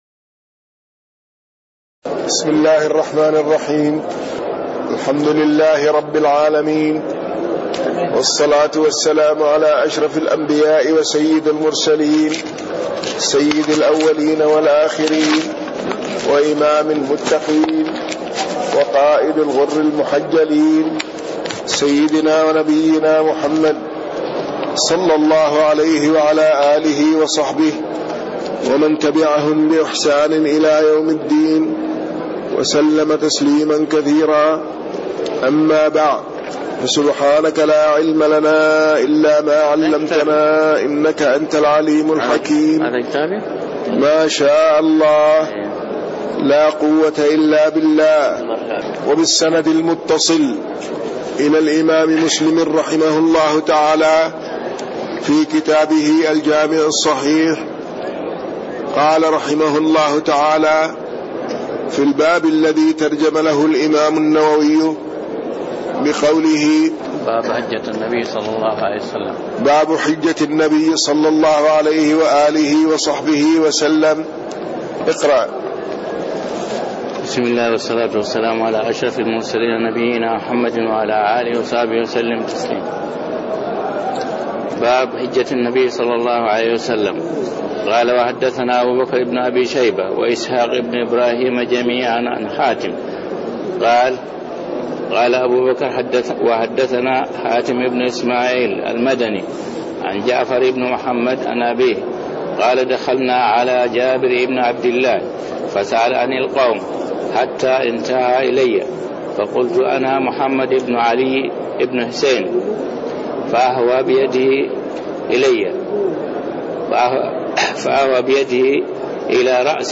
تاريخ النشر ١٨ شوال ١٤٣٣ هـ المكان: المسجد النبوي الشيخ